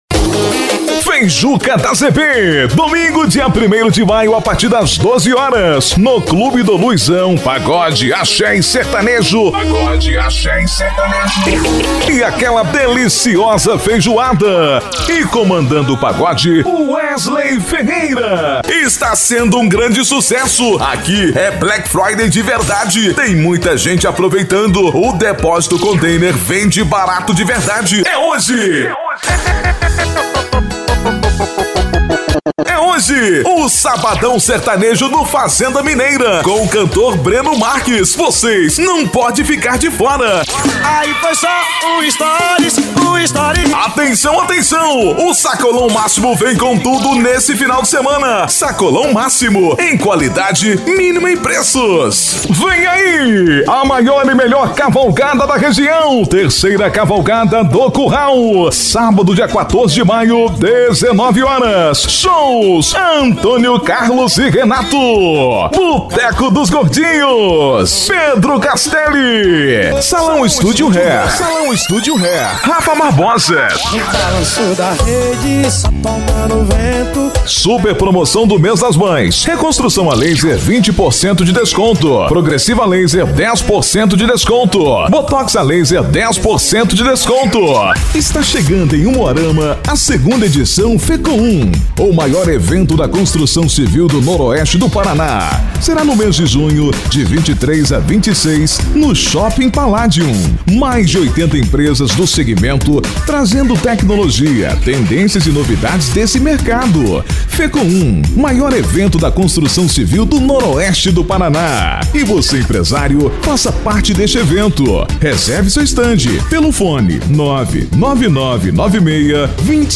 DEMO IMPACTO :
Spot Comercial
Vinhetas
Estilo(s):
Impacto
Animada